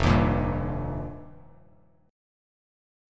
F7sus2sus4 chord